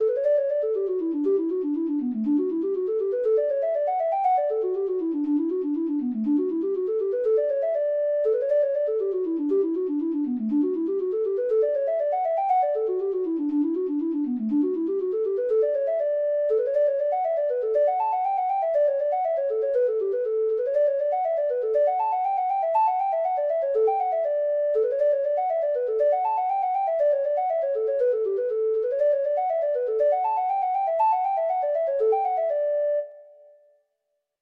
Traditional Trad. The Jolly Little Boy (Irish Folk Song) (Ireland) Treble Clef Instrument version
Traditional Music of unknown author.
Irish